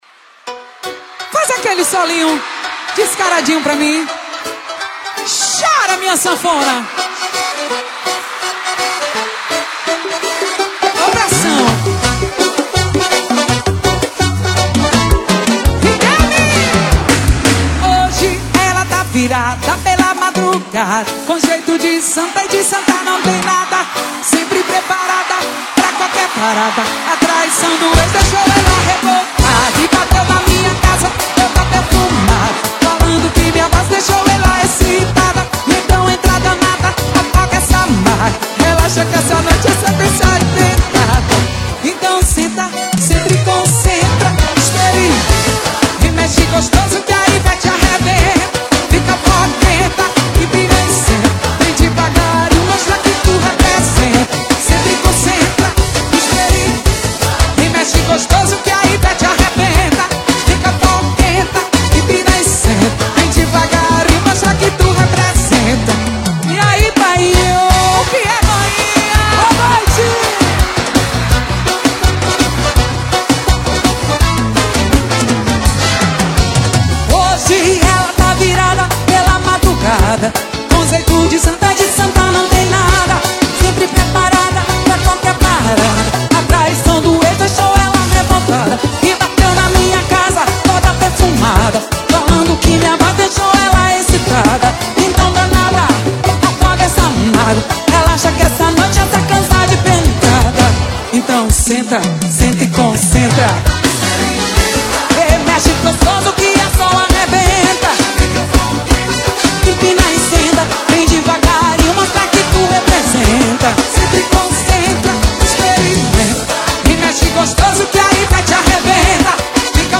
Forro Para Ouvir: Clik na Musica.